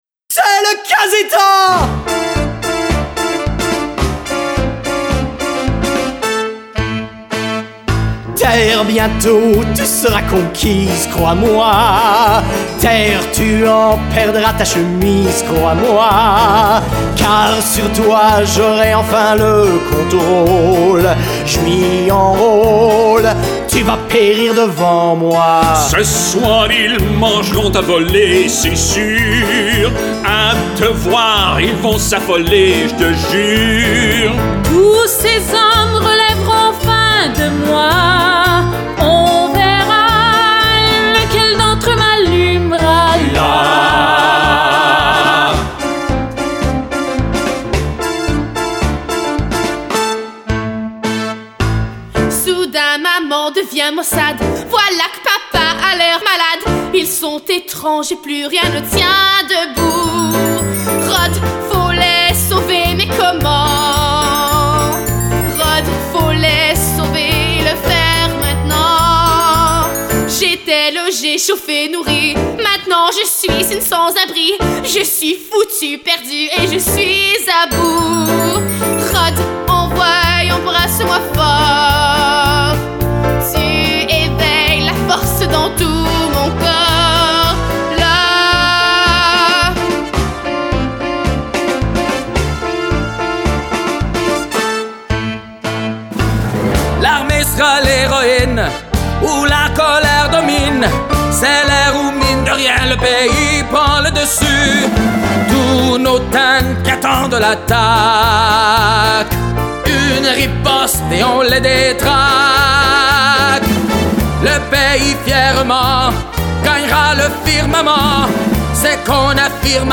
song in French
this one is the finale of act one